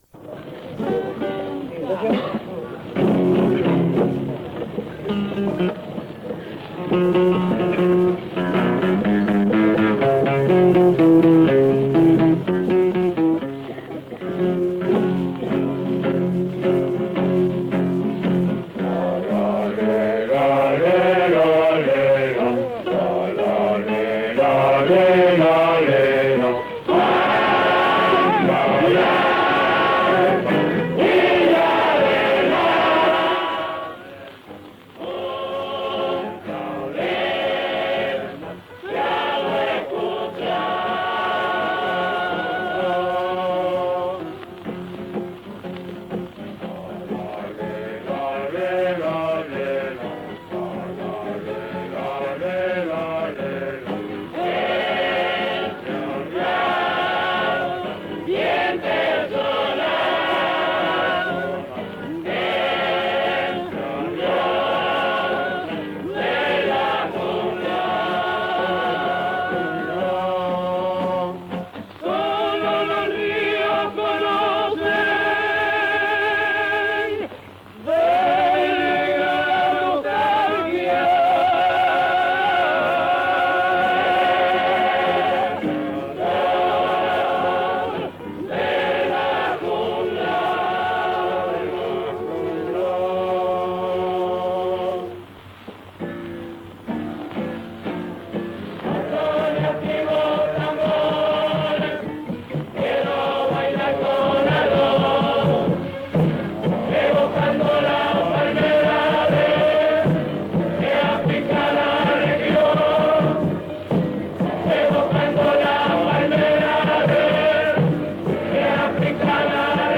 Especie: canción de comparsa
Ejecutante, edad, instrumental: Conjunto lubolo “Negros Orientales”, guitarras y coro mixto
Localidad: Montevideo (en el Teatro de Verano del Parque Rodó), departamento de Montevideo, Uruguay
El audio se encuentra interrumpido al final en el original.